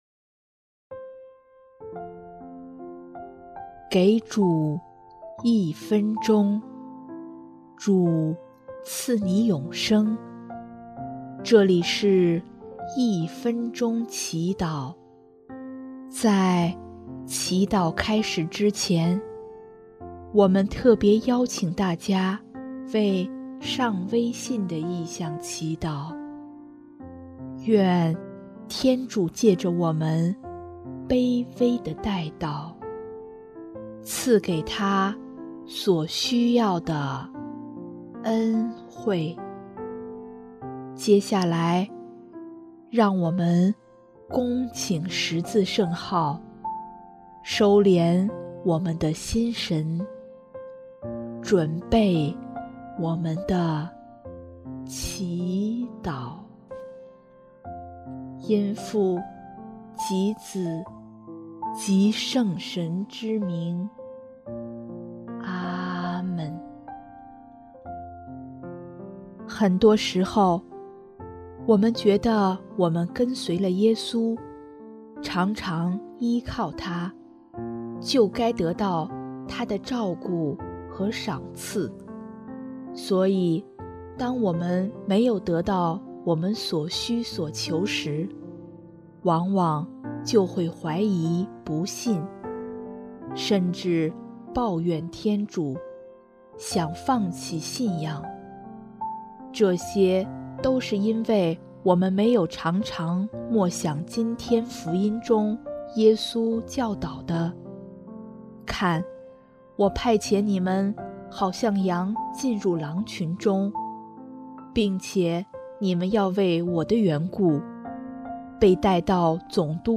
【一分钟祈祷】|7月12日 为信仰作证
音乐：主日赞歌《若翰的见证》（上微信：求主除去自己的心魔，使自己过上正常的生活，也使自己的家庭和睦如初）